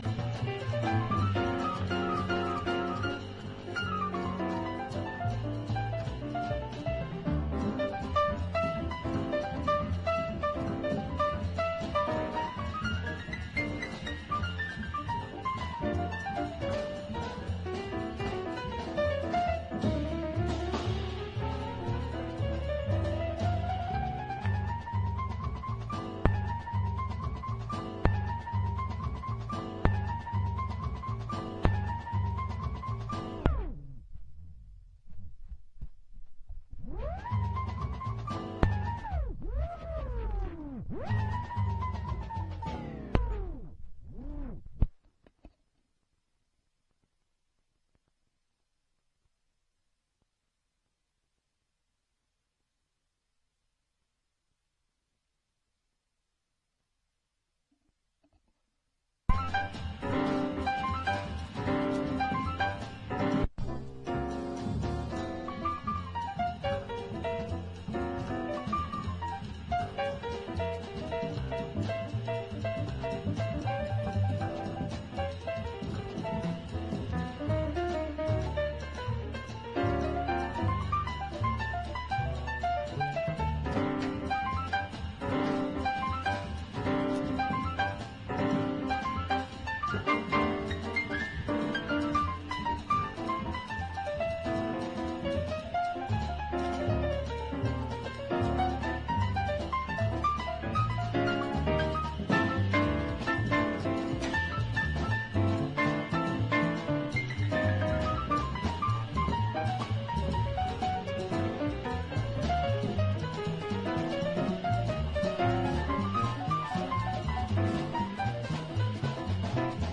ROCK / 60'S (UK)